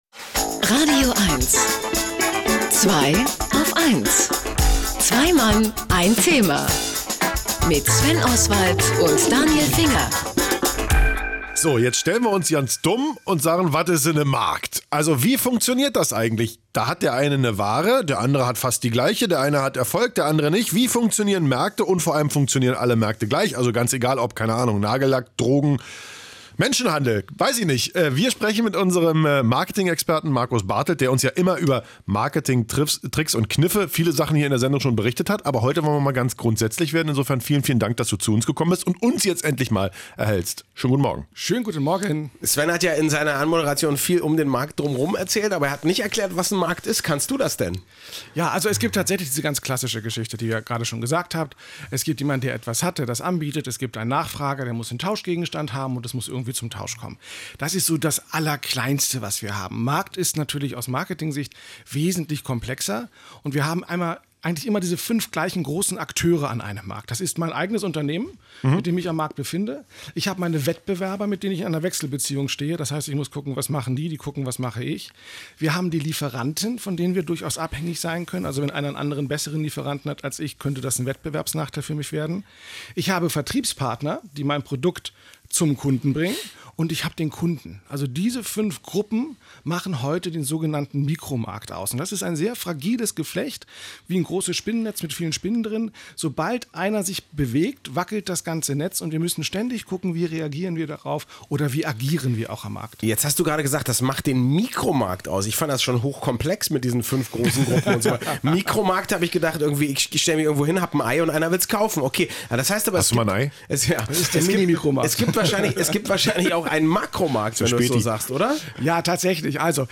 Diesmal ging es bei „Zweiaufeins“ in „radioeins“ um mein Thema….
Denn Marketing ist ja untrennbar mit Märkten verbunden. Wer unser Gespräch heute verpasst hat, kann es hier nachhören: